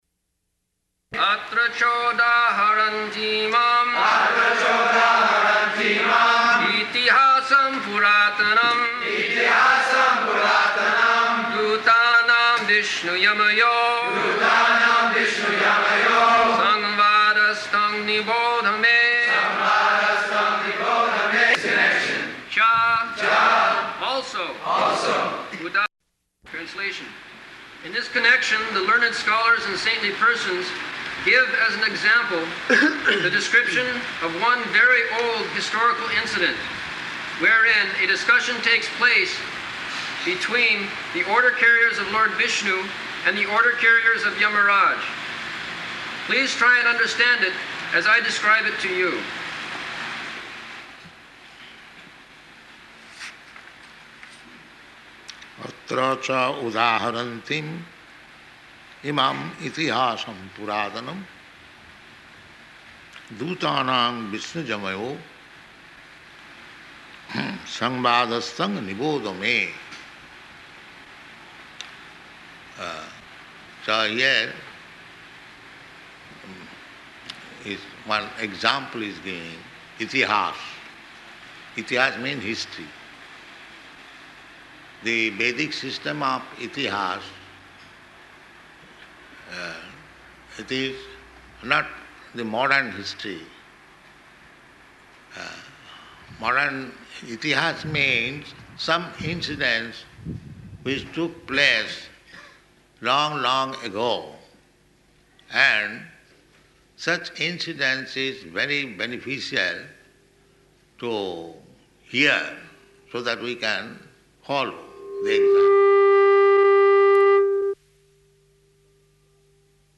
Location: Chicago
[loud feedback noise] That is called itihāsa.